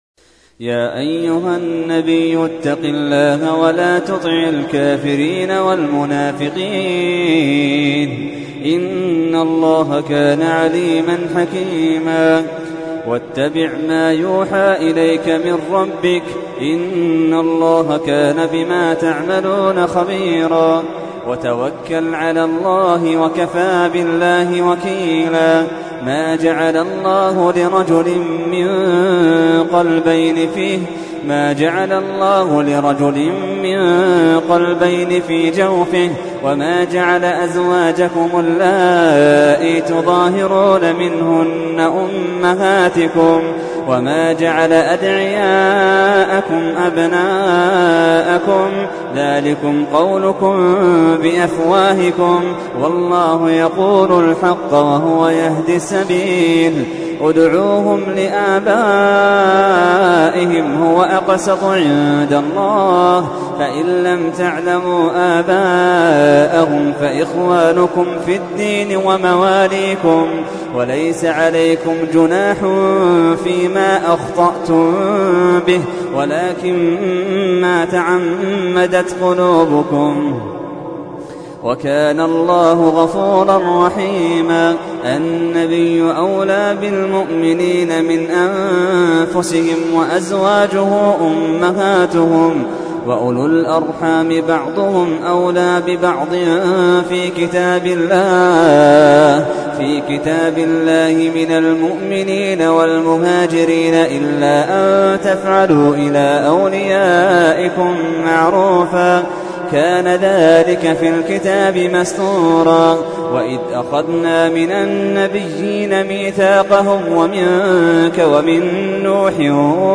تحميل : 33. سورة الأحزاب / القارئ محمد اللحيدان / القرآن الكريم / موقع يا حسين